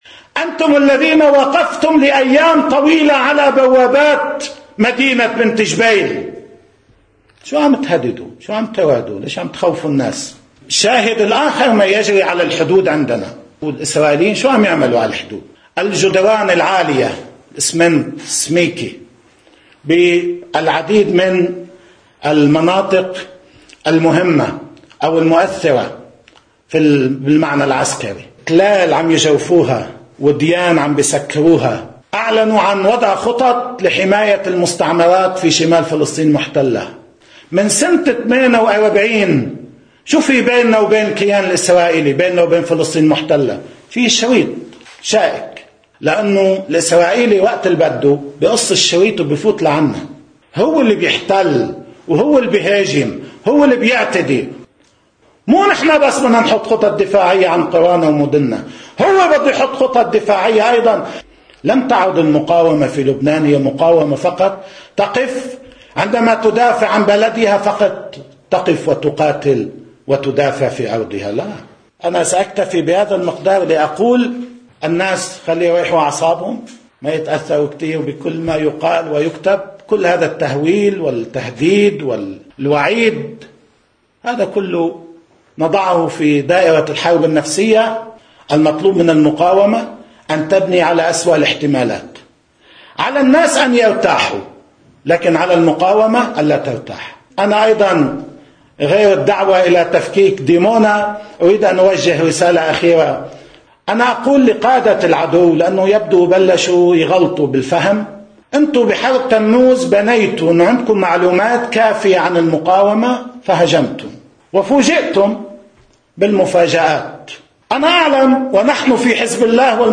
مقتطف من حديث السيّد نصر الله في مهرجان سادة النصر: